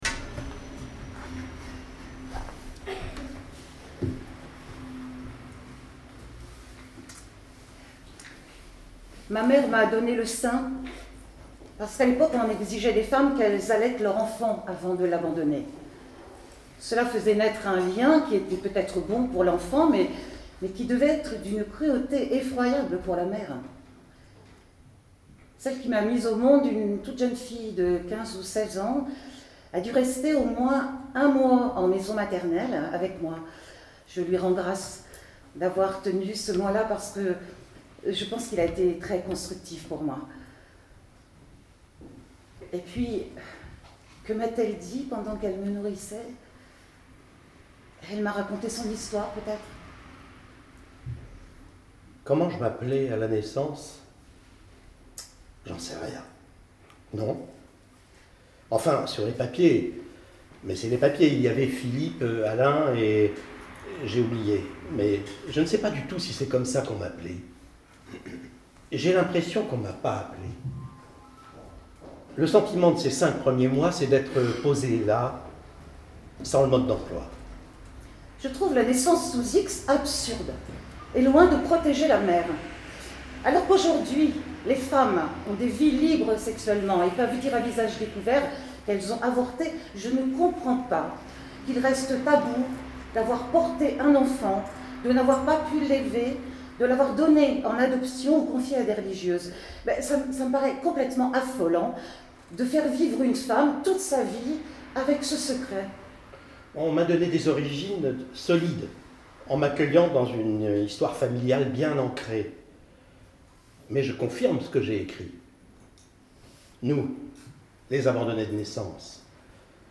Extrait audio de la lecture des entretiens tirés du livre